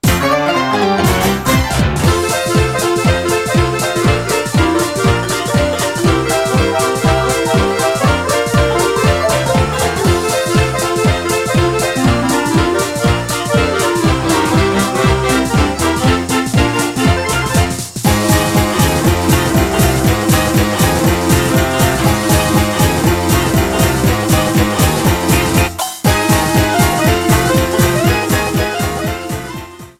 Ripped from the ISO
Faded in the end